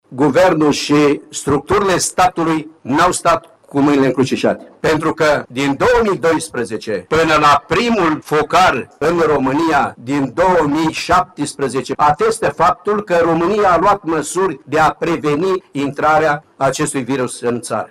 Ministrul Agriculturii, Petre Daea, a declarat recent că nu are ce să-şi reproşeze în privinţa pestei porcine. El a spus că guvernul a acţionat corect, rapid şi eficient, dar că despăgubirile întârzie din cauză că nu a fost avizată rectificarea bugetară.